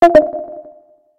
ticker_alert.ogg